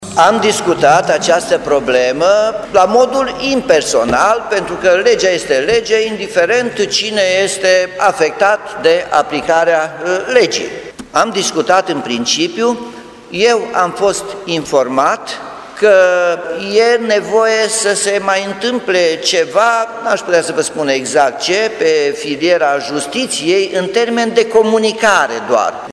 Primarul Nicolar Robu susține că a analizat situația funcționarilor trimiși în judecată și trebuie să mai aștepte până la luarea unei decizii în privința suspendării: